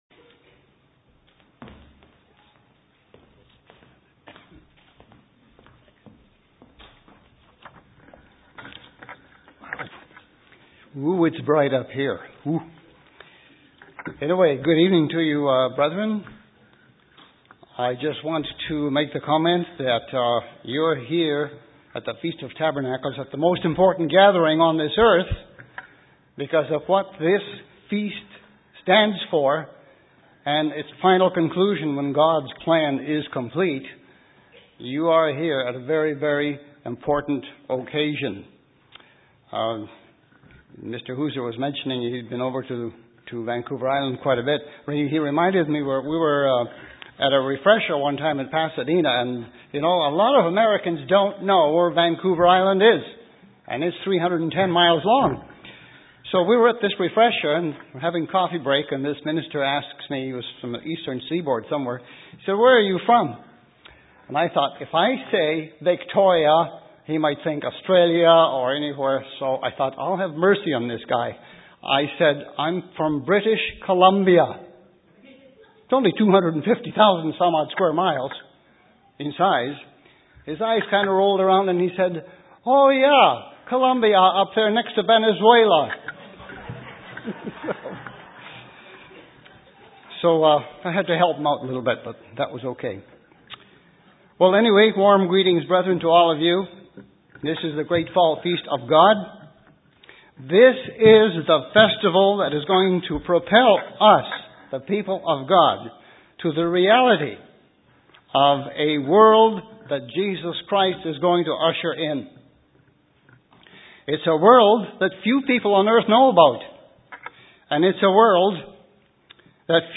This sermon was given at the Kelowna, British Columbia 2010 Feast site.